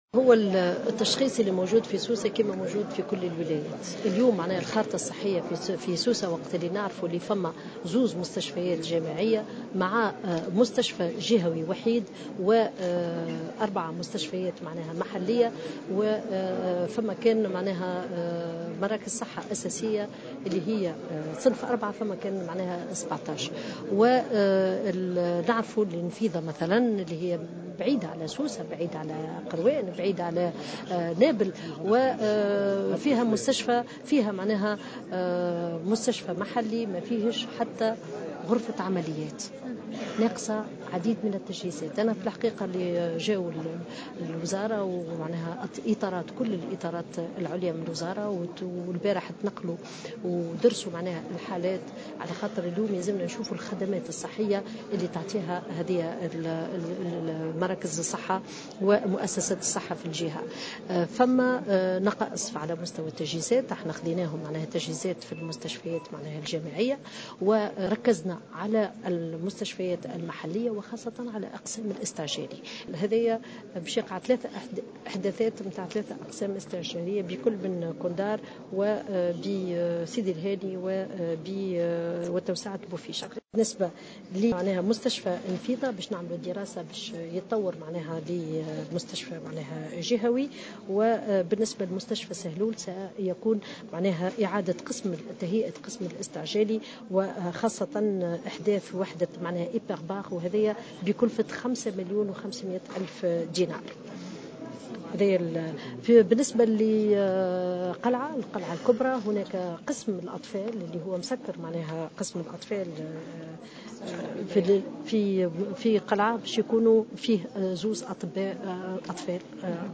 وأضافت الوزيرة في تصريح لمراسلة الجوهرة اف ام، أنه سيتم تعزيز عدة مستشفيات بتجهيزات طبية على غرار سيارات إسعاف، إضافة إلى إعادة فتح قسم طب الأطفال بالمستشفى المحلي بالقلعة الكبرى.